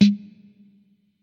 Tag: drumcomputer 小鼓 葡萄酒